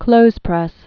(klōzprĕs, klōthz-)